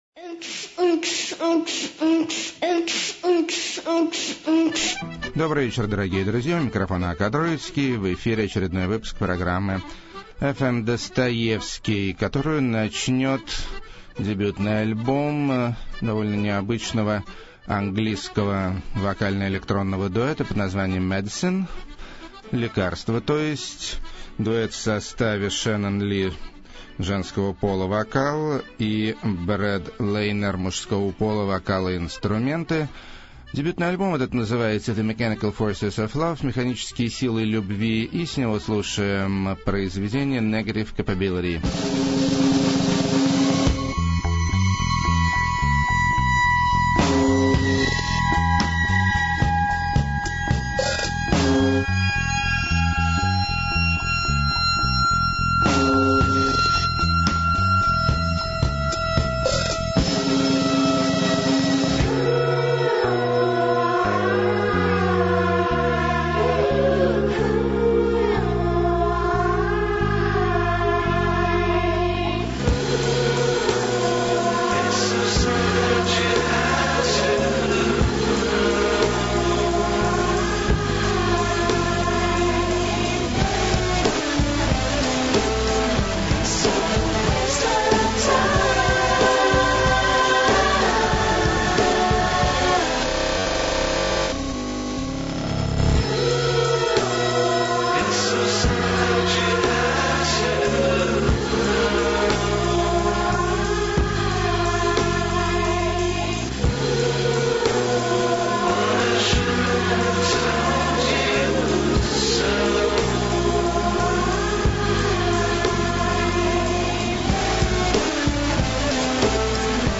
The Weird Edge Of Electro-pop
Post-punk, Fresh Sound
Rocky Accordeon
Schmaltzy Country Surf
Adventurous Electro
Lush Pop Flamenco Flavoured
Post-krautrock
Intellectual Cosmopolitan Trip-pop
Leftfield Indie Eclecticism